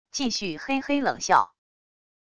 继续嘿嘿冷笑wav音频